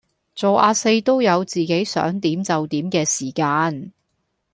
Гонконгский 812